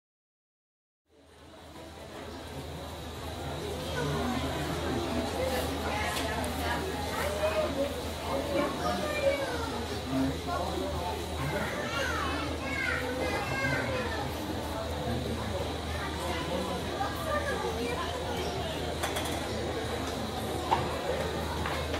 На этой странице собраны звуки магазина игрушек: весёлая суета, голоса детей, звон кассы, фоновые мелодии.
Шум игрушек в магазине для детей